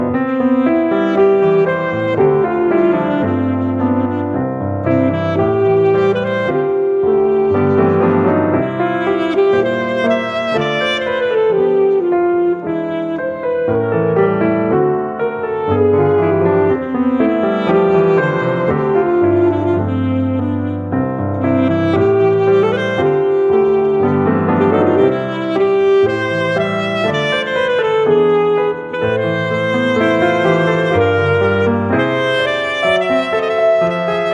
arranged for piano and light instrumentals